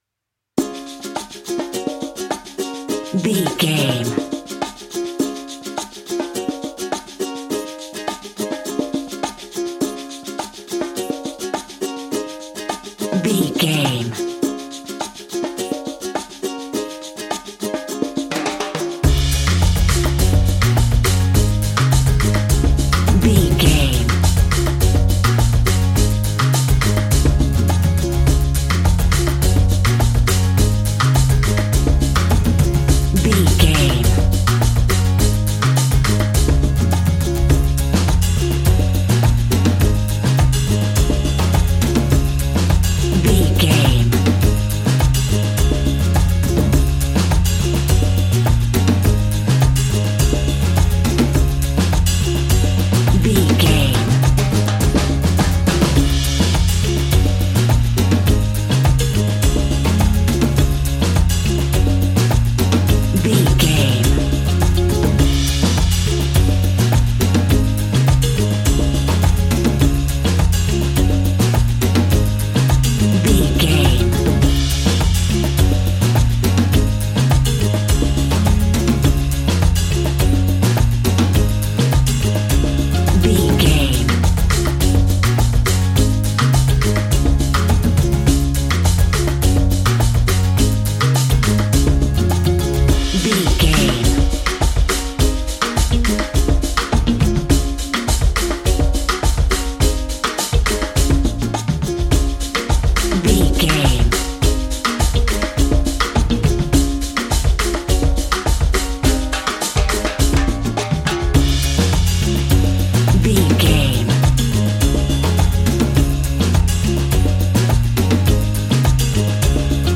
Ionian/Major
D♭
cheerful/happy
mellow
drums
electric guitar
percussion
horns
electric organ